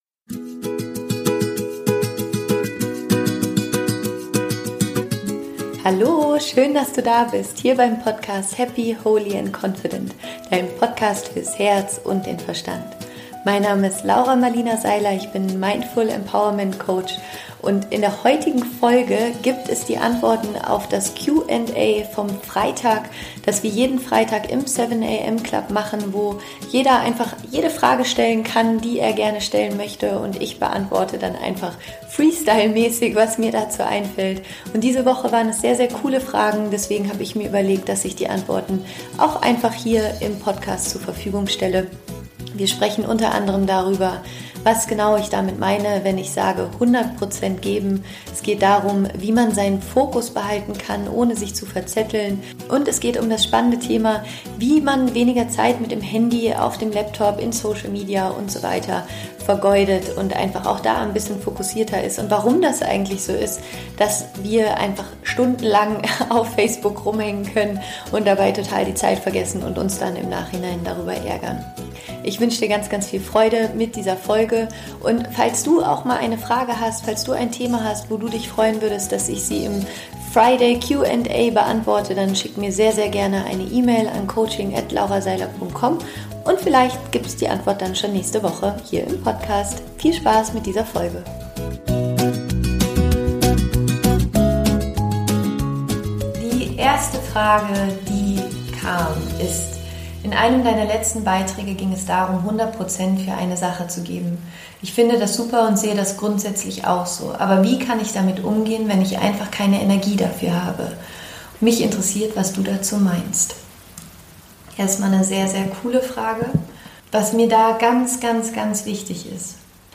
Ich beantworte live alle Fragen, die während der Woche gestellt wurden.
Weil die Fragen ziemlich cool sind, gibt's den Mitschnitt hier im Podcast zum nachhören.